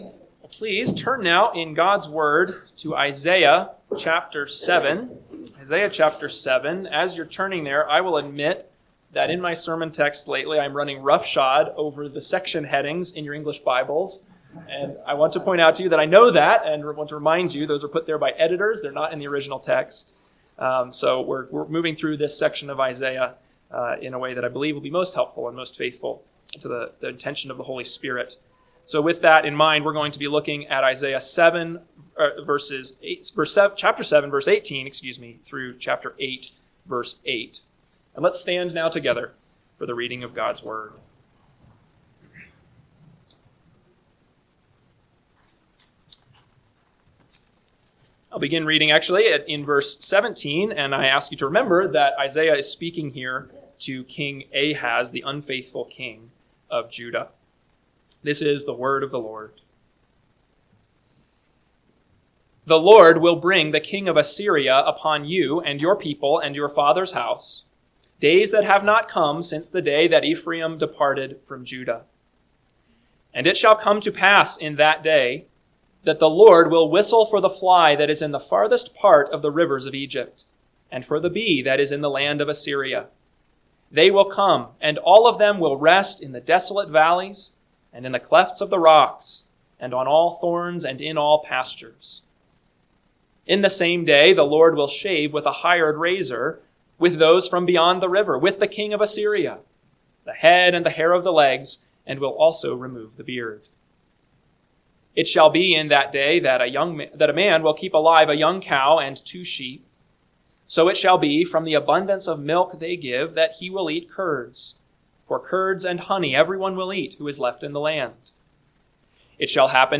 AM Sermon – 12/28/2025 – Isaiah 7:18-8:8 – Northwoods Sermons